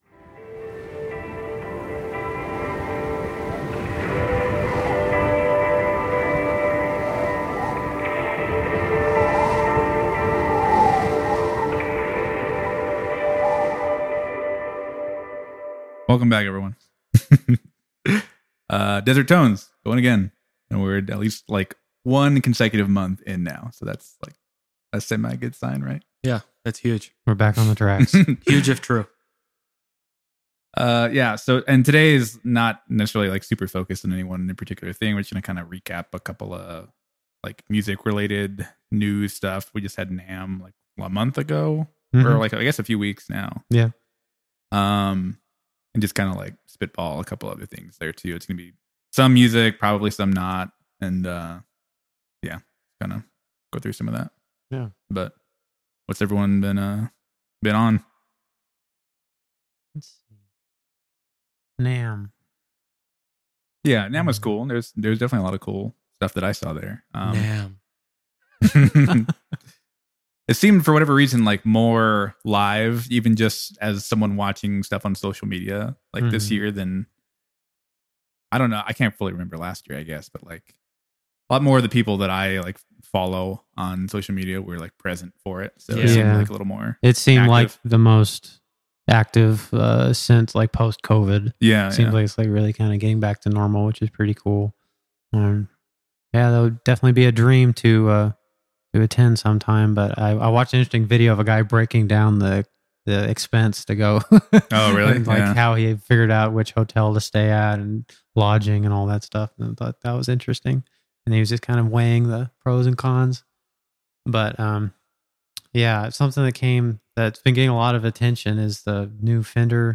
Desert Tones is a podcast where three friends discuss music, gear, tone chasing, and just about anything else.